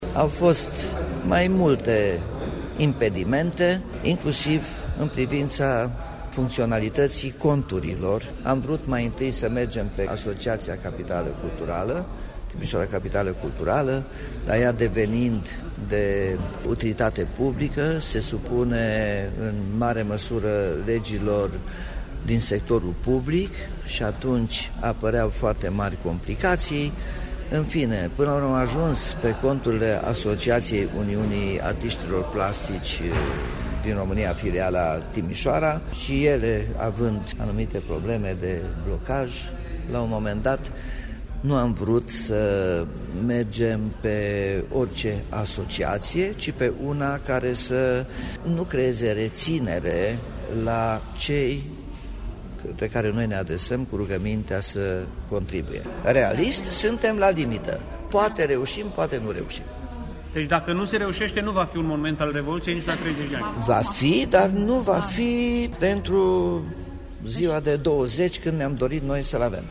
Edilul a explicat motivele pentru care campania de strângere de fonduri a început atât de târziu: